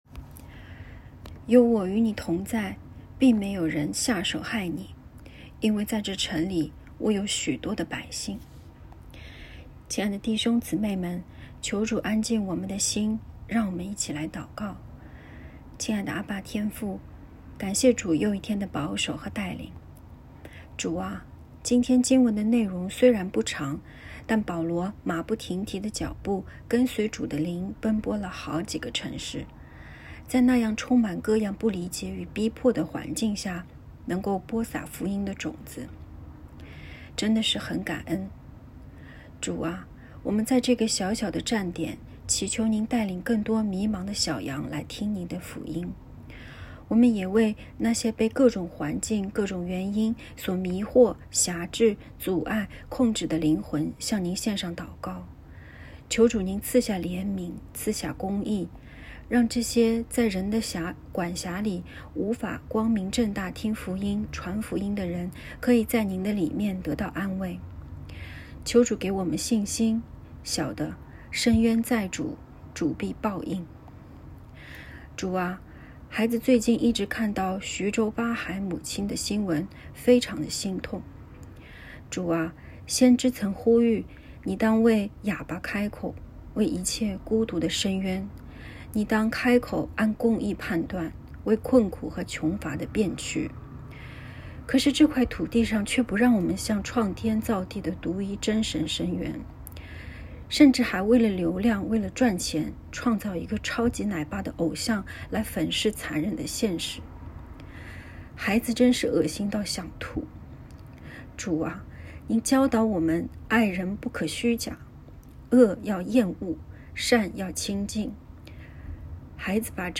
✨晚祷时间✨2月15日（周二）